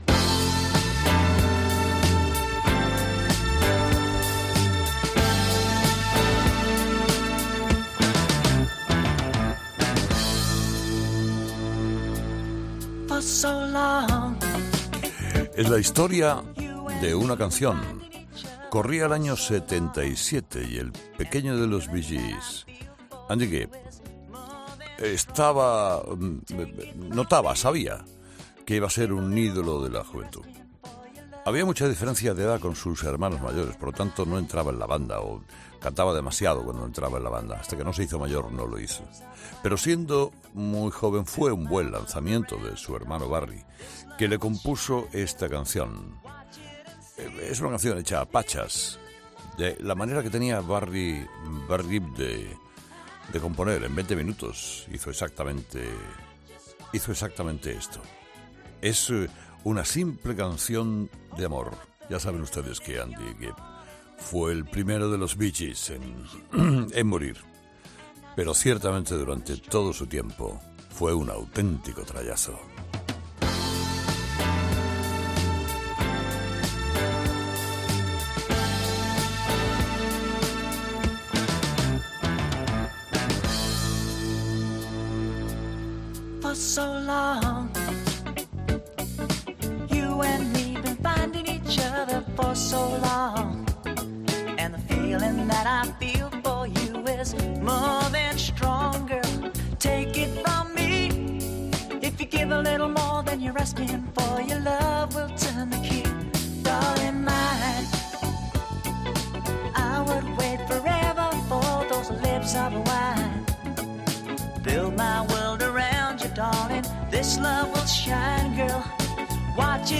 Es una simple canción de amor.